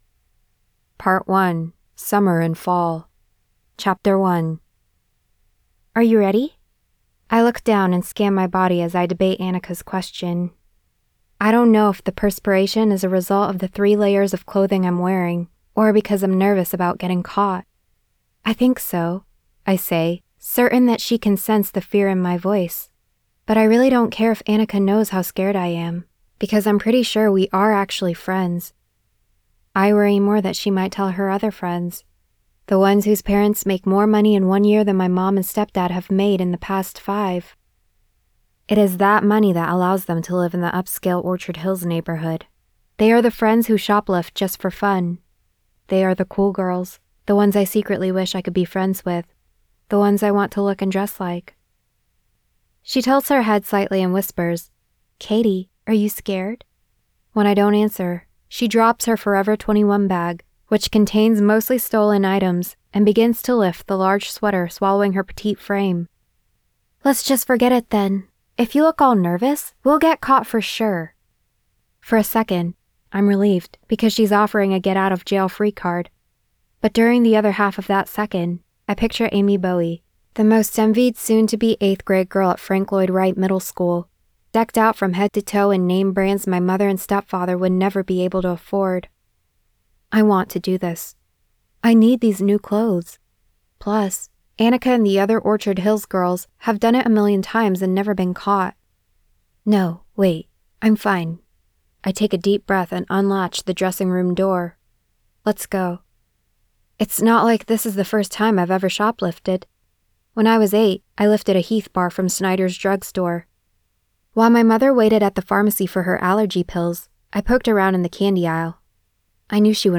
Listen to a sample of the Don’t Call Me Kit Kat Audiobook: